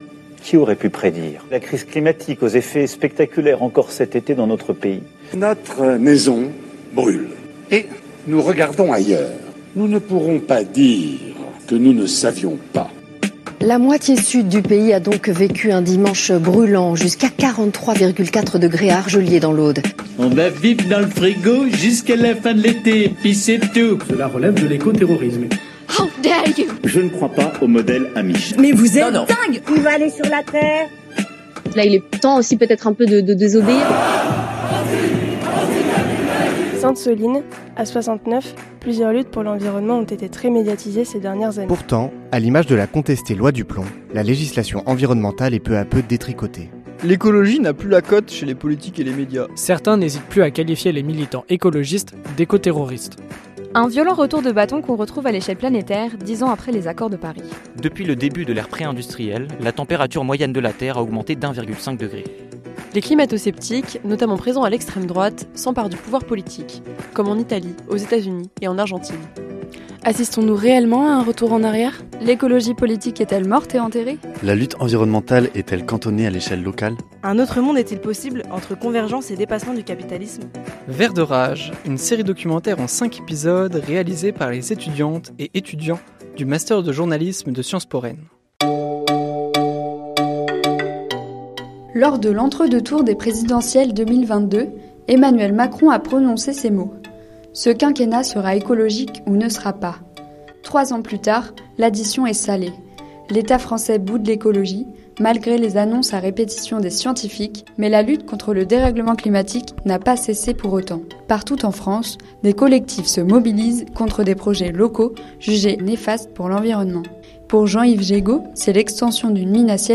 "C'est la lutte locale" est le deuxième épisode de la série documentaire Vert de rage.